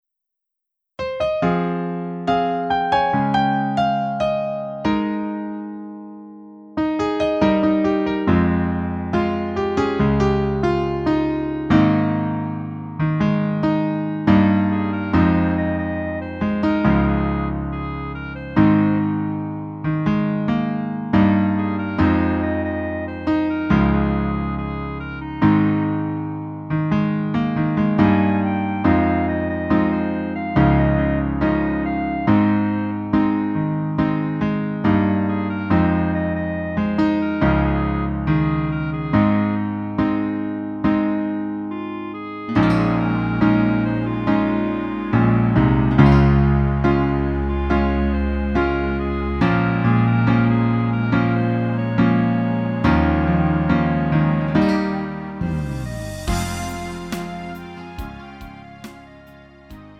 음정 -1키 4:22
장르 가요 구분 Lite MR